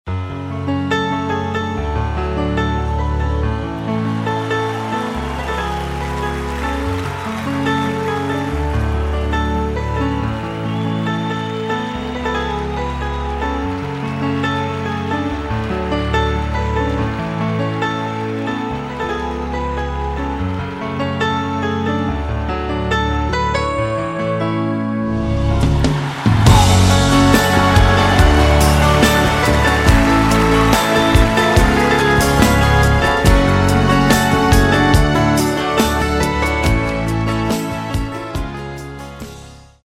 инструментал.mp3